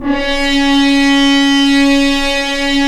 Index of /90_sSampleCDs/Roland L-CDX-03 Disk 2/BRS_F.Horns FX+/BRS_FHns Mutes
BRS F.HRNS04.wav